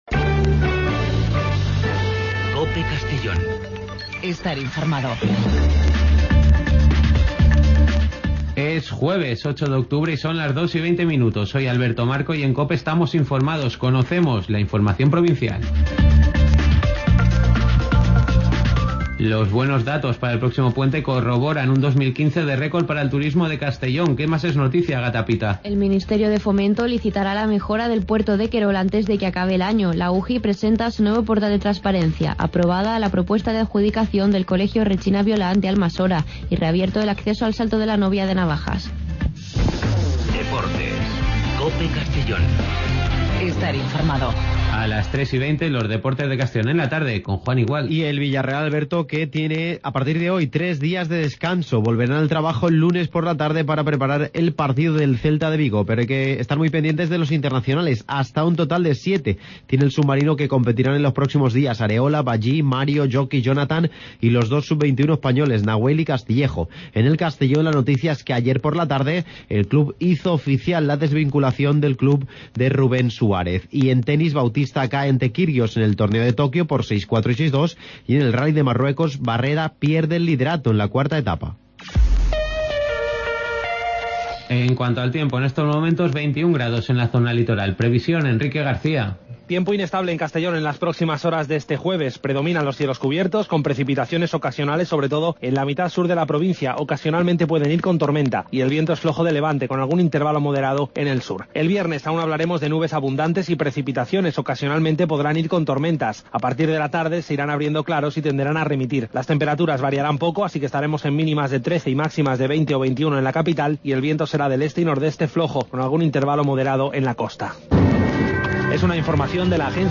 Espacio informativo a nivel provincial, con los servicios informativos de COPE en la provincia de Castellón.